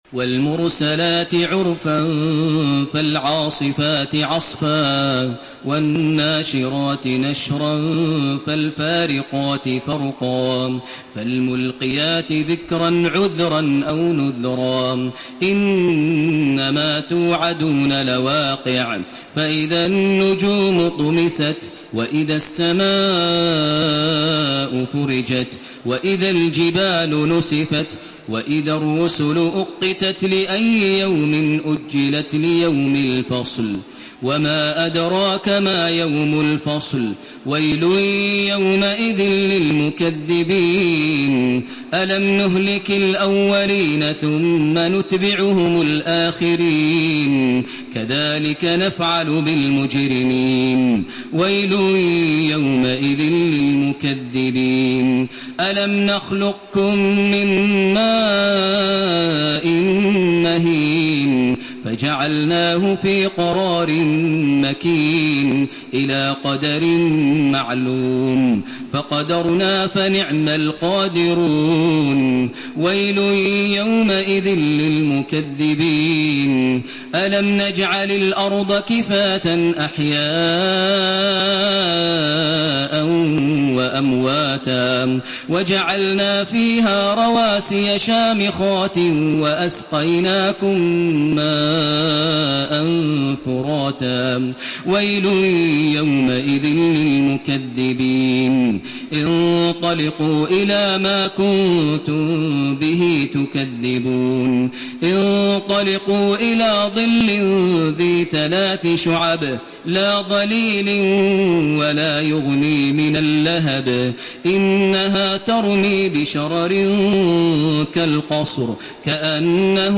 Surah Al-Mursalat MP3 in the Voice of Makkah Taraweeh 1432 in Hafs Narration
Murattal